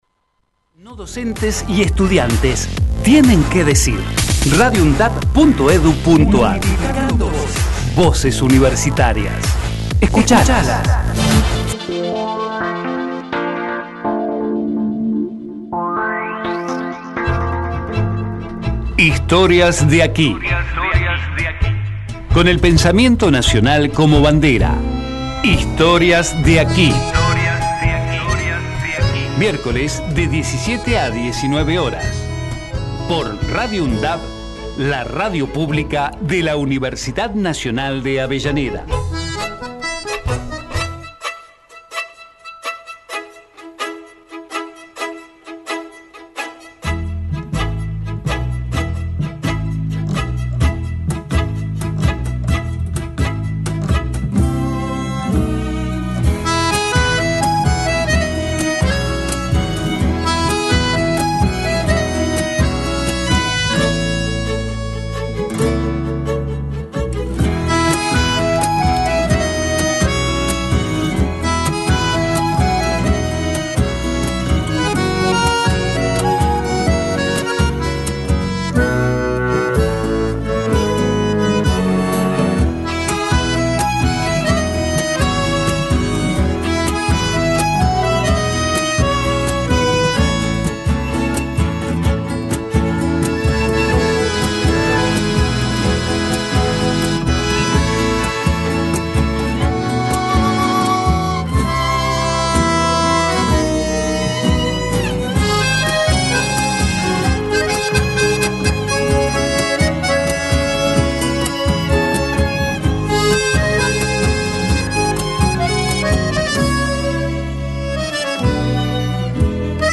Historias de aquí Texto de la nota: Historias de aquí Con el pensamiento nacional como bandera. Música regional, literatura y las historias que están presentes en la radio.